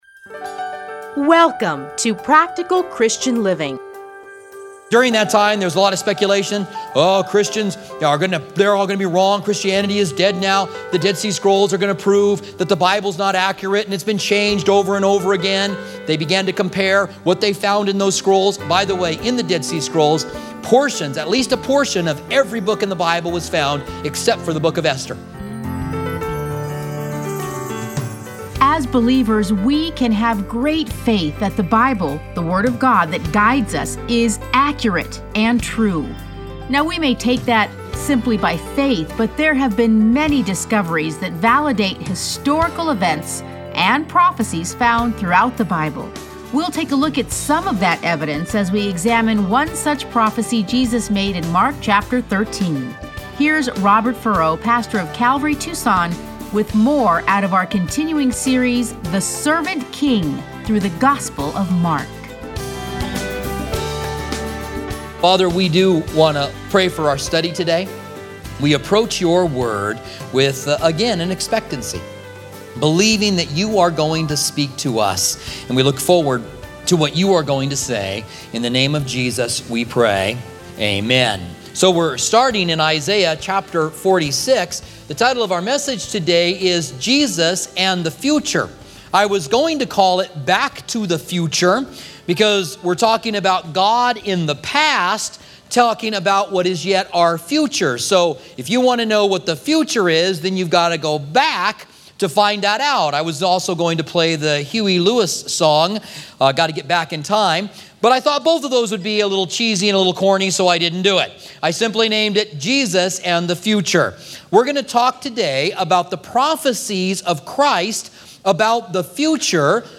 Listen to a teaching from Mark 13.